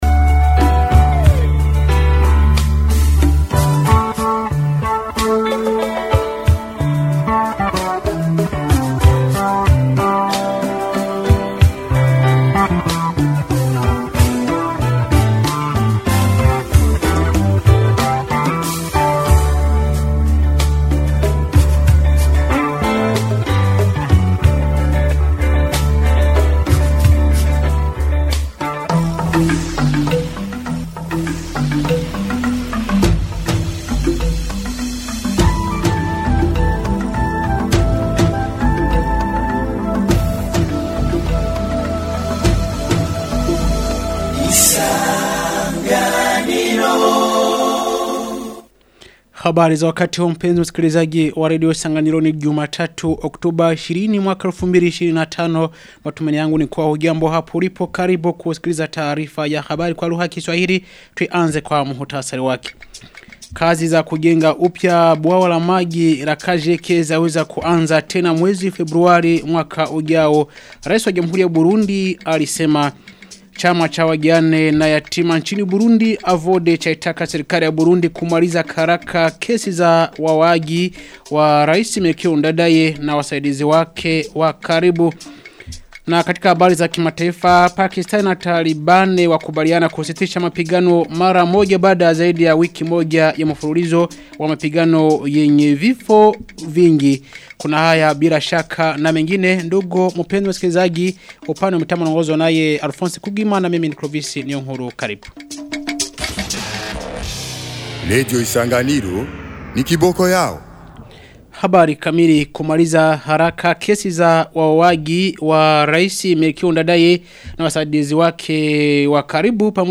Taarifa ya habari ya tarehe 20 Oktoba 2025